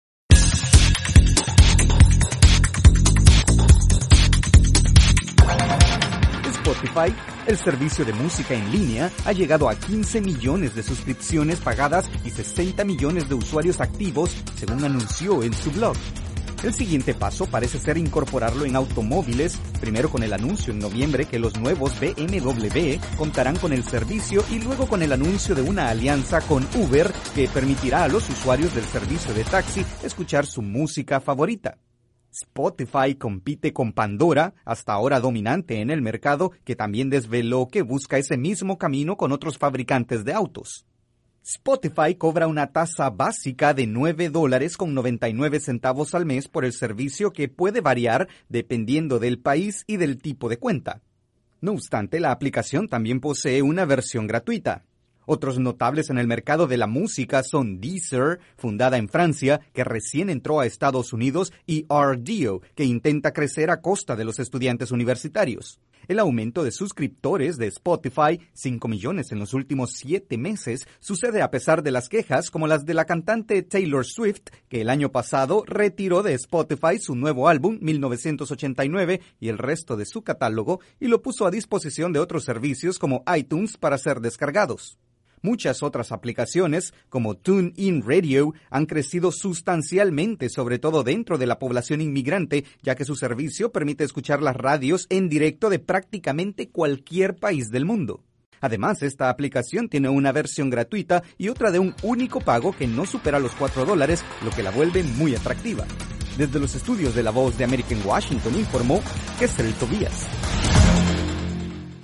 La aplicación escuchar música anunció que logró un nuevo máximo de 15 millones de suscriptores a su servicio de radio y que el siguiente paso será incorporarlo en automóviles como el BMW y en los taxis de Uber. Desde los estudios de la Voz de América informa